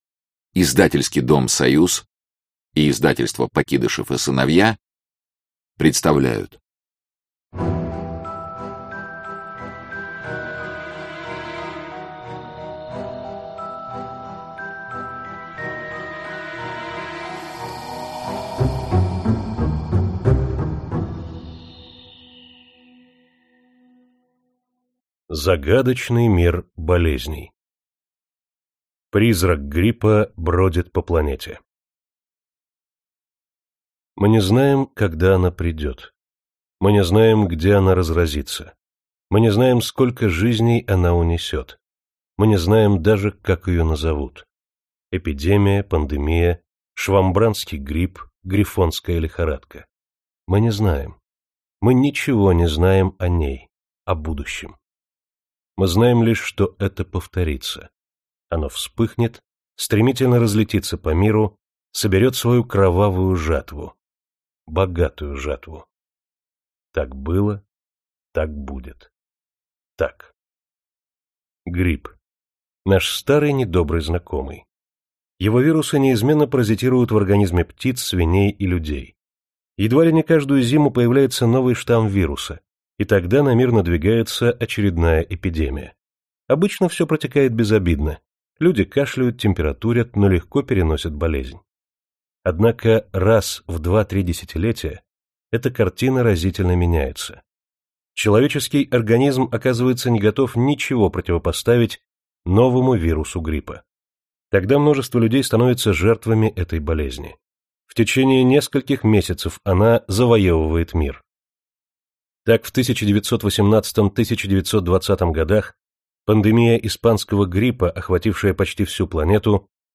Аудиокнига Сто загадок современной медицины для чайников | Библиотека аудиокниг